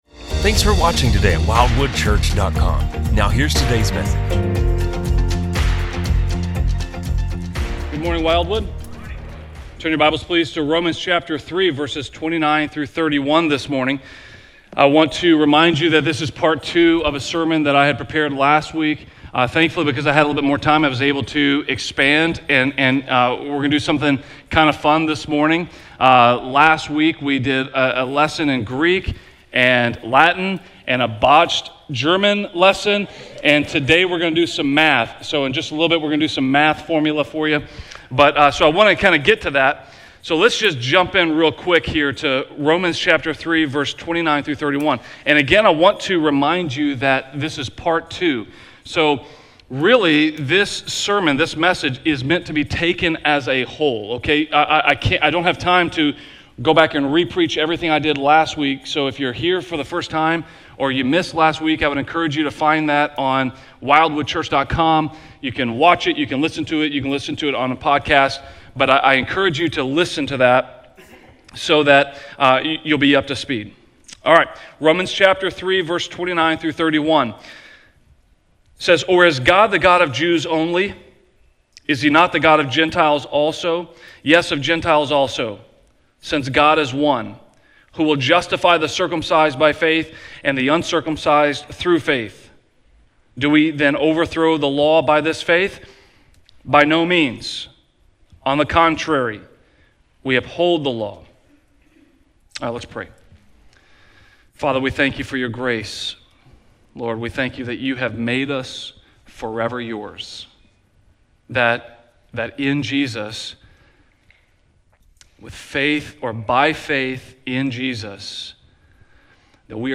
Join us on our second-part of a two-part sermon, “By Faith, Alone.”